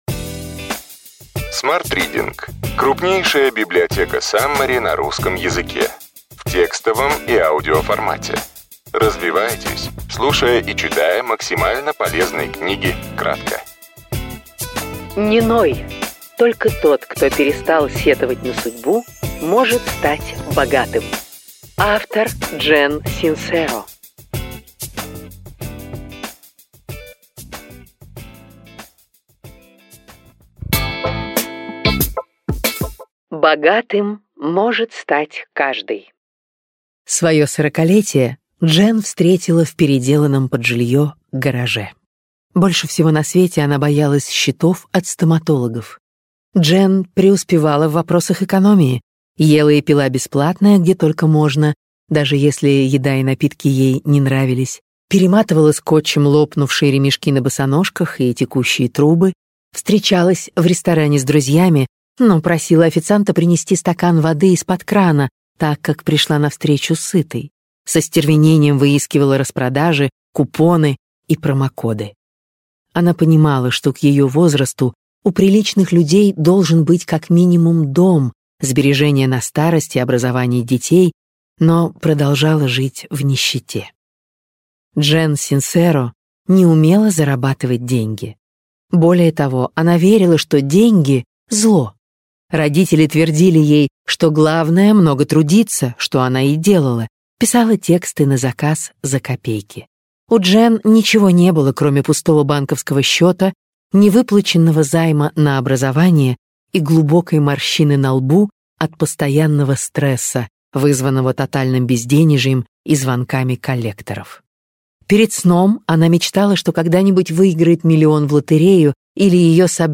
Аудиокнига Не ной. Только тот, кто перестал сетовать на судьбу, может стать богатым. Джен Синсеро. Саммари | Библиотека аудиокниг